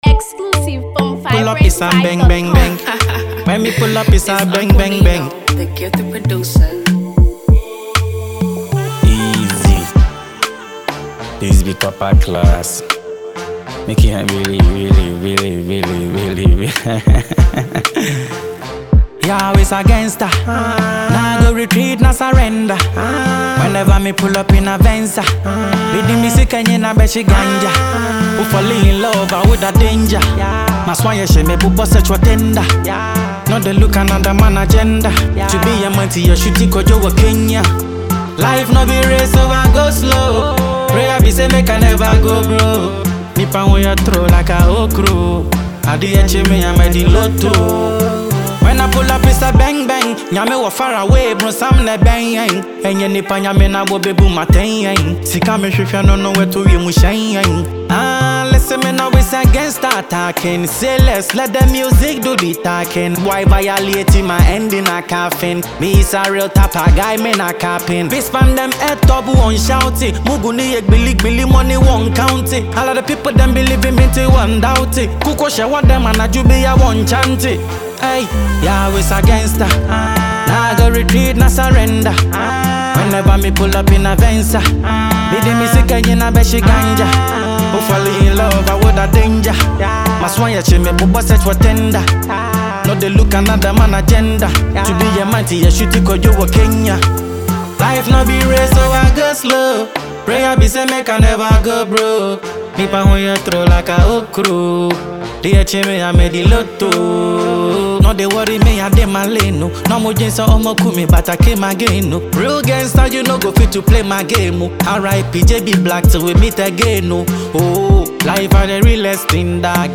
sweet melodies